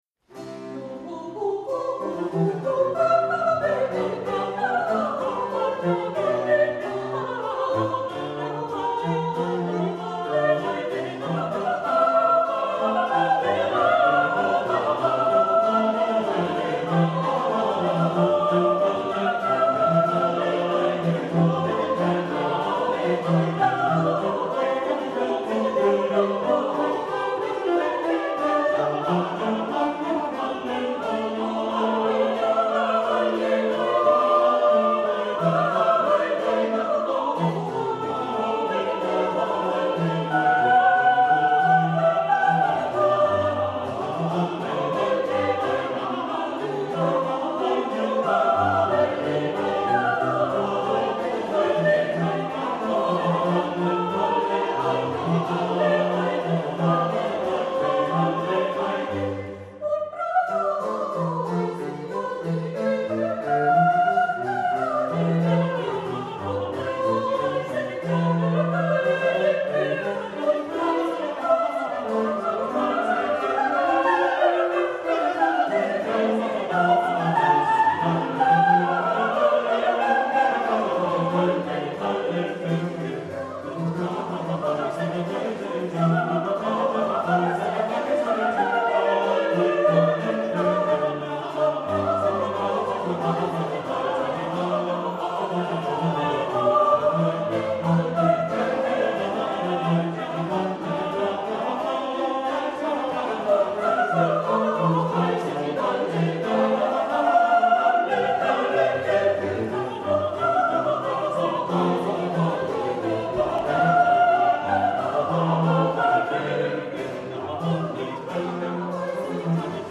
primo violino
secondo violino
viola
violoncello
contrabbasso
cembalo
GenereCori